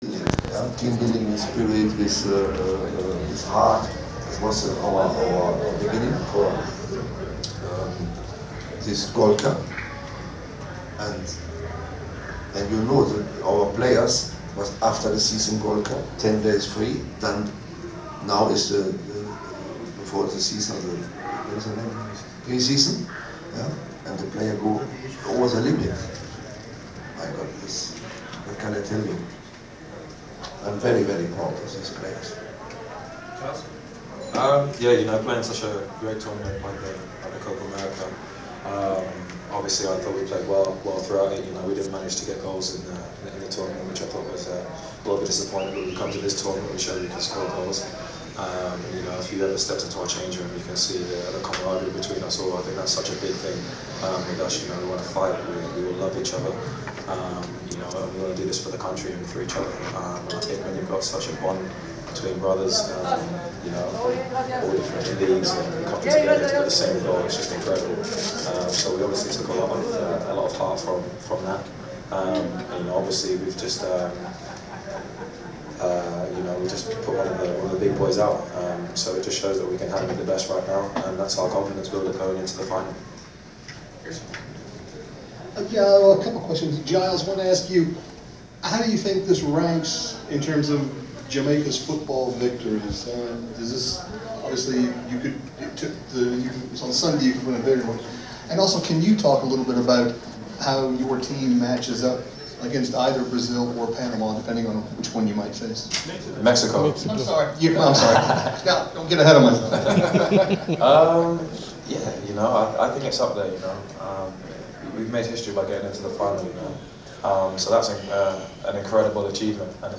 Inside the Inquirer: Postmatch presser with Jamaica Men’s Soccer team 7.22.15
The Sports Inquirer attended the postmatch presser of Jamaica men’s soccer team following its 2-1 win over the United States in the semifinals of the Gold Cup in Atlanta at the Georgia Dome on July 22. Attending the presser was head coach Winfried Schafer and Giles Gordon Barnes who scored the game-winning goal.